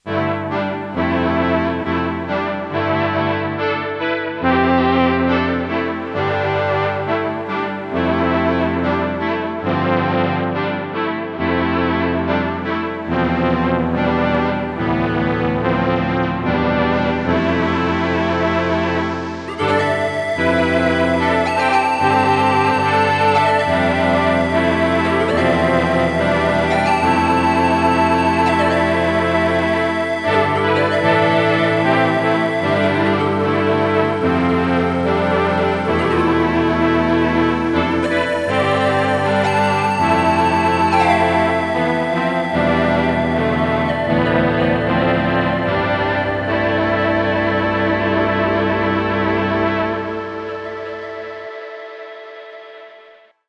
Synth Stuff Yamaha PSS480 Music Station
Here are some demos/improvs with some Chinese cheap MOoeR shimverb pedal and chorus pedal connected to the PSS480 through a Signature edition Soundcraft mixer…the PSS480 really sparkles with some chorus effect over it:
Majestic synths & Panflute patch
The PSS480 has the best pan flute patch on any synth – its so perfect, especially when with portamento…
LegoweltYamahaPSS480demo-BestPanFlutePatchInTheWorld.mp3